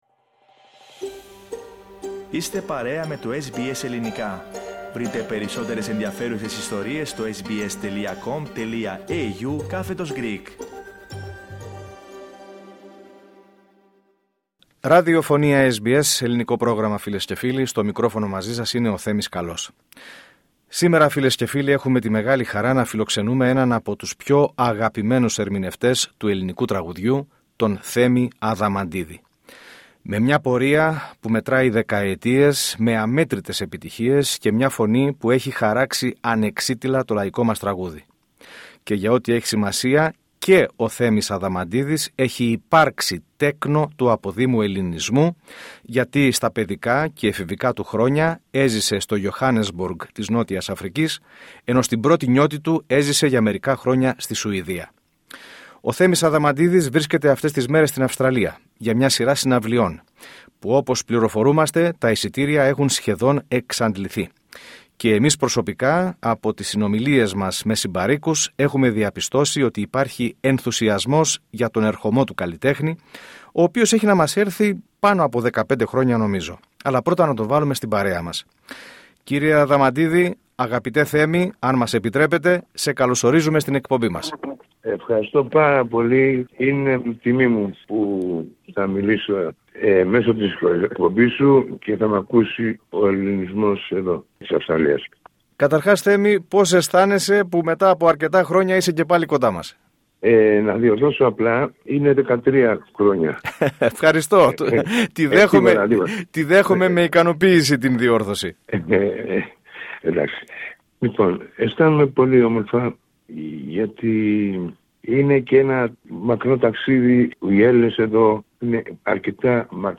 Ένας από τους πιο αγαπημένους ερμηνευτές του ελληνικού τραγουδιού, ο Θέμης Αδαμαντίδης, μίλησε αποκλειστικά στο SBS Greek, με αφορμή την περιοδεία του στην Αυστραλία μετά από 13 χρόνια.